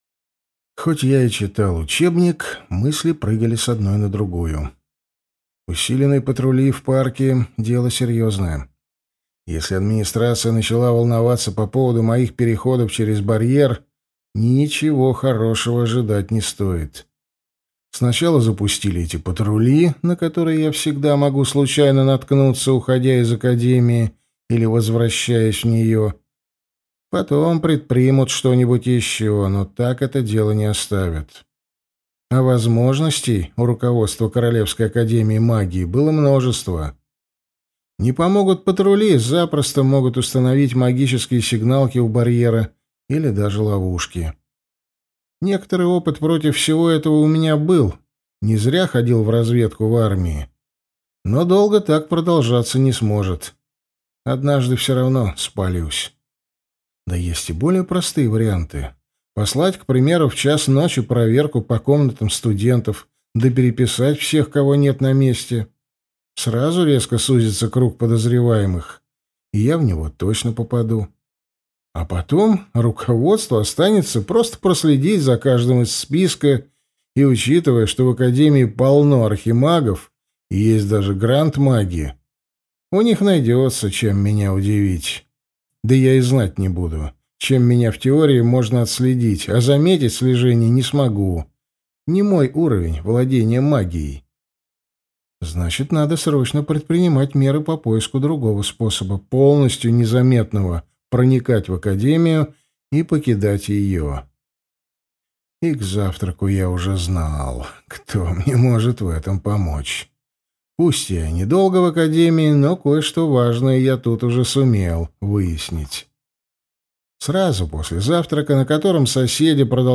Аудиокнига Антидемон. Книга 3 | Библиотека аудиокниг